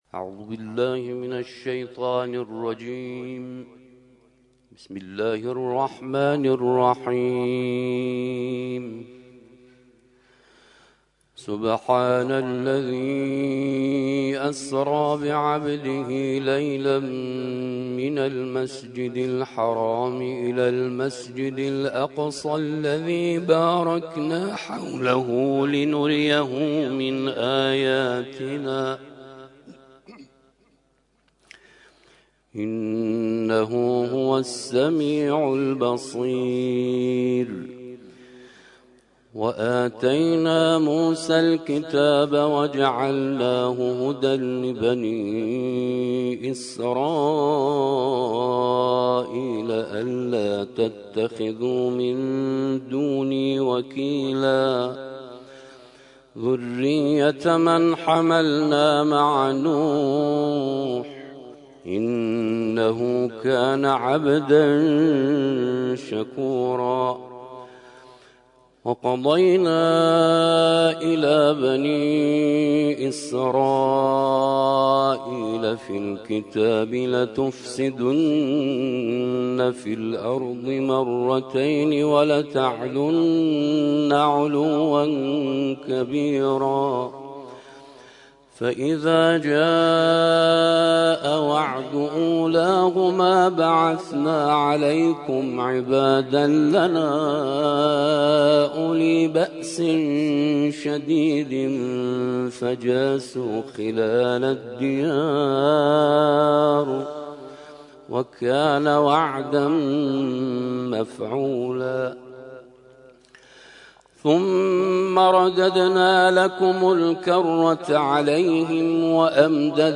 ترتیل خوانی جزء ۱۵ قرآن کریم - سال ۱۳۹۹